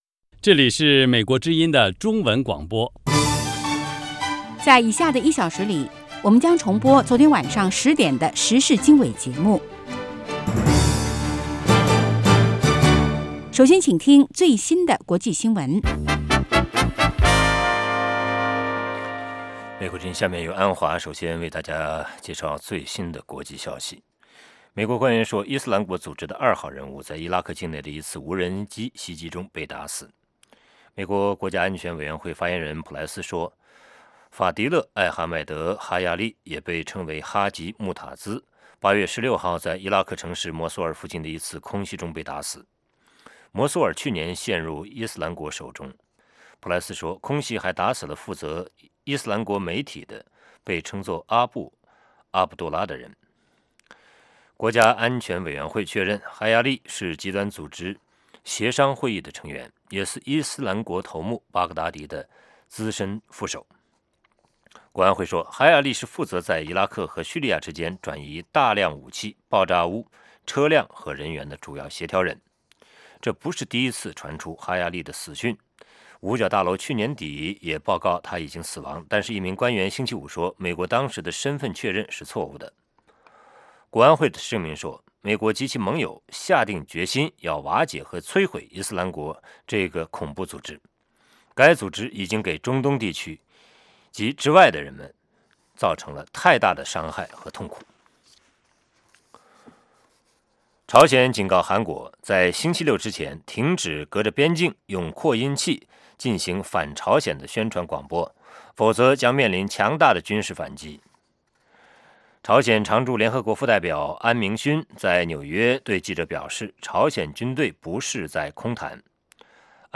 北京时间早上8-9点广播节目 这个小时我们播报最新国际新闻，并重播前一天晚上10-11点的时事经纬节目。